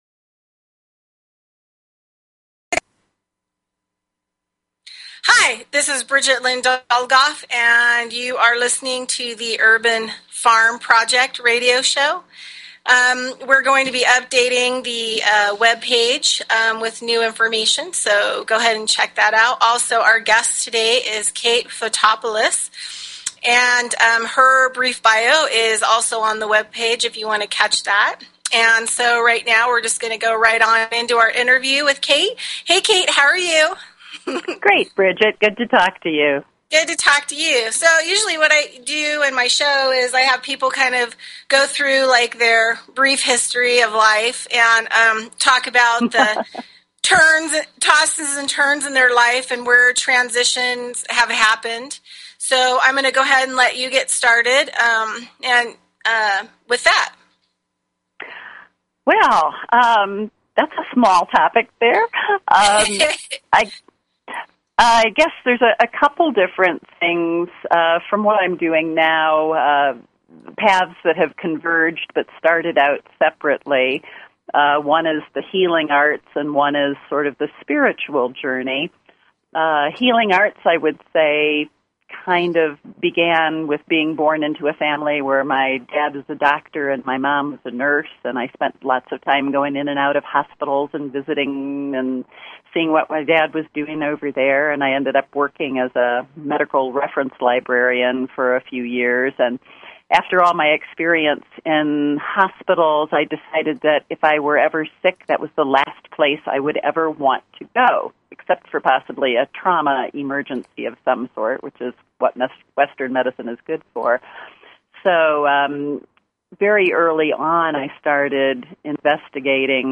Talk Show Episode, Audio Podcast, The_Urban_Farm_Project and Courtesy of BBS Radio on , show guests , about , categorized as